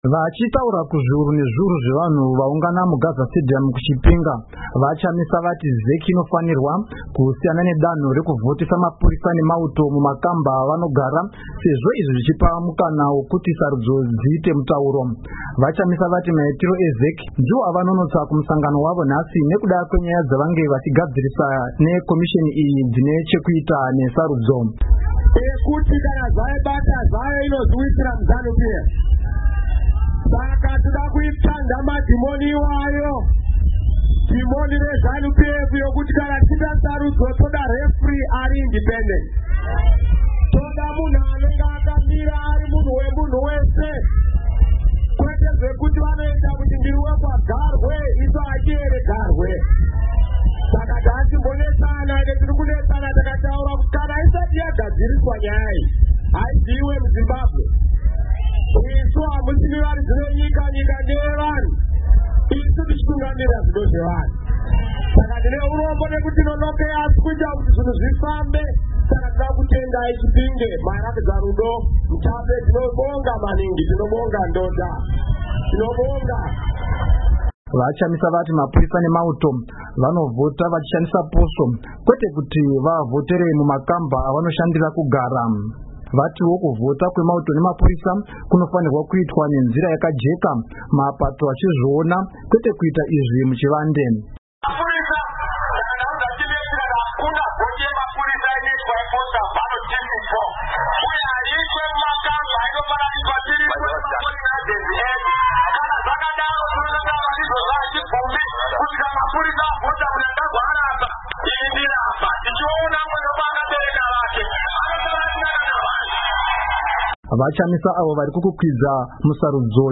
Vachitaura kuzviuru nezviuru zvevanhu vaungana muGaza stadium kuChipinga, VaChamisa vati Zec inofanirwa kusiyana nedanho rekuvhotesa mapurisa nemauto mumakamba avanogara sezvo izvi zvichipa mukana wekuti sarudzo dziite mutauro.